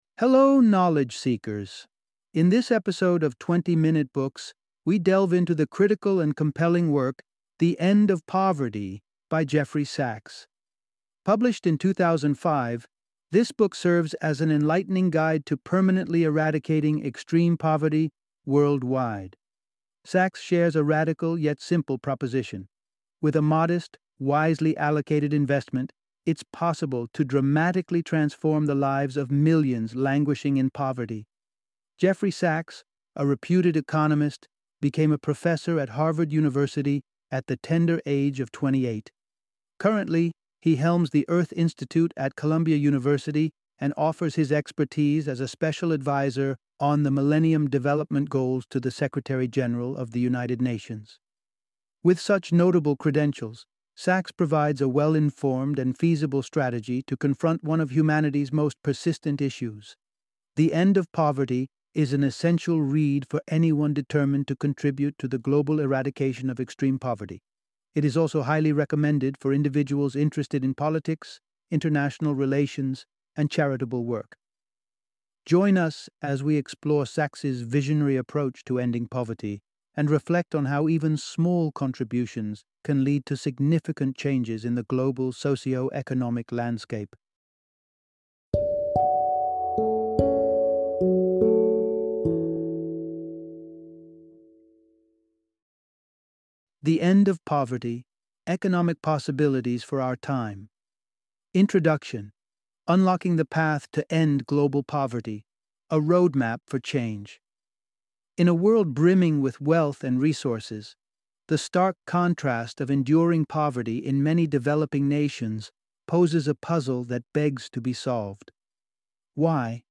The End of Poverty - Audiobook Summary